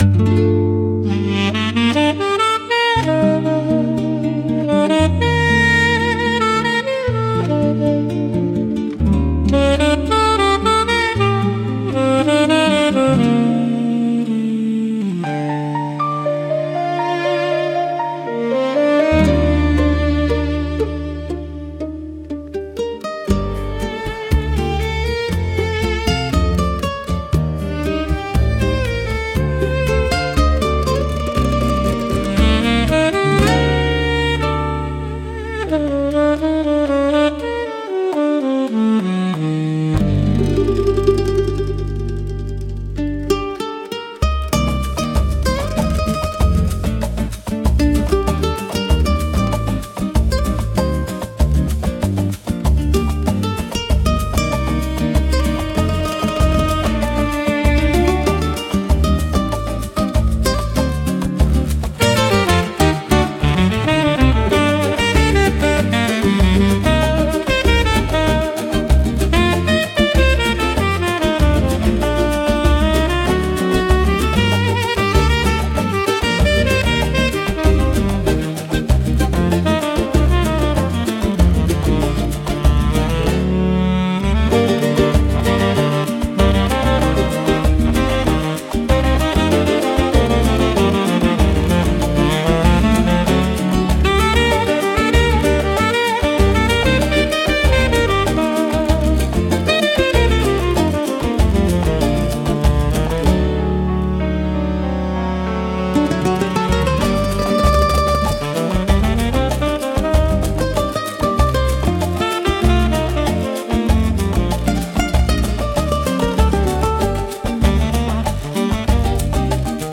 instrumental 9